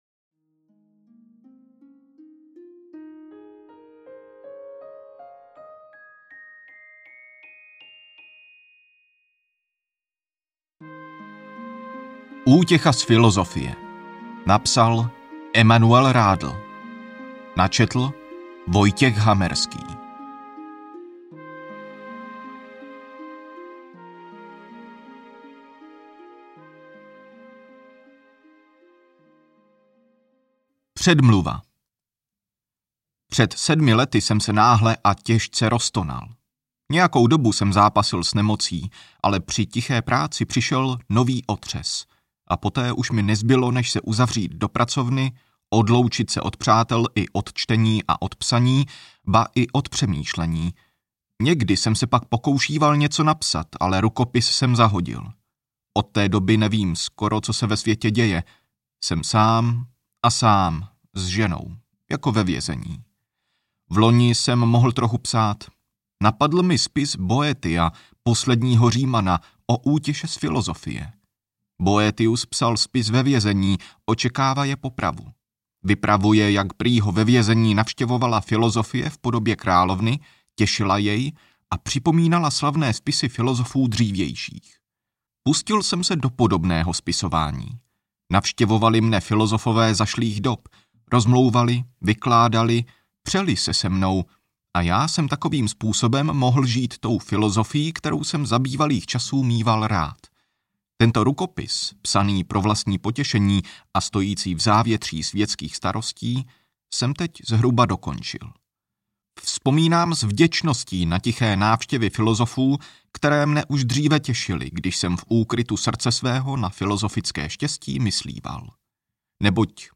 Útěcha z filosofie audiokniha
Ukázka z knihy